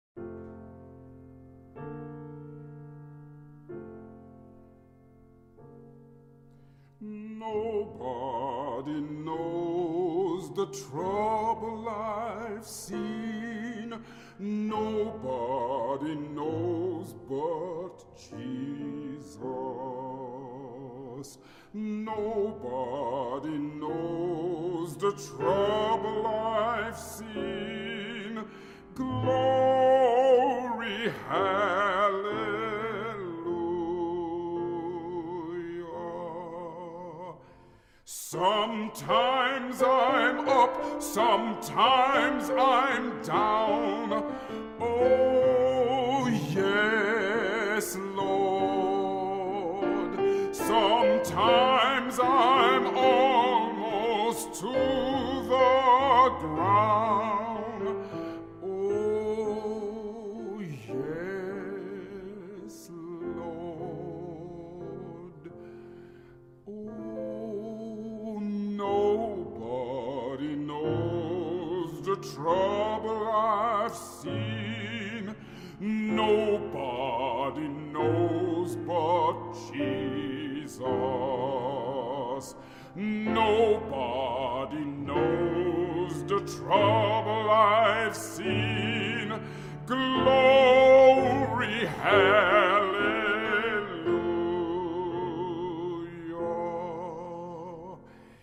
nobody-knows-de-trouble-ive-seen-negro-spiritual-mp3cut.net_.mp3